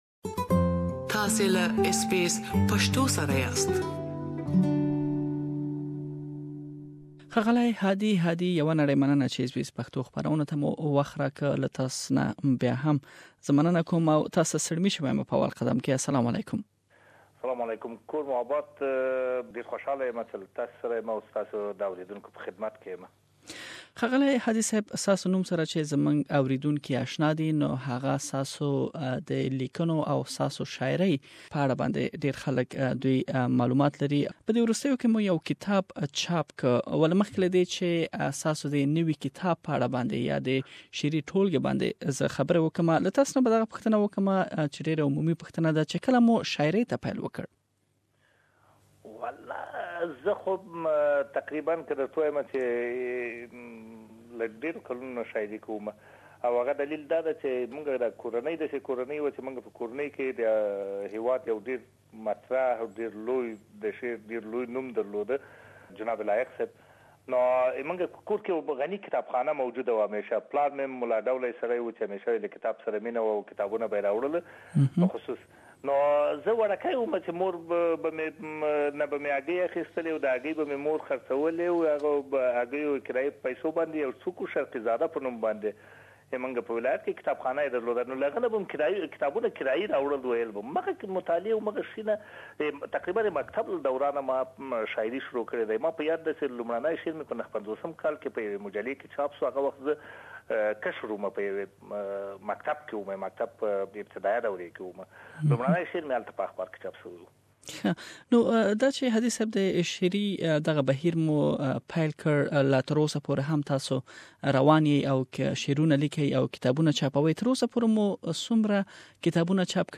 Interview with famous poet and writer- Part one